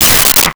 Space Gun 02
Space Gun 02.wav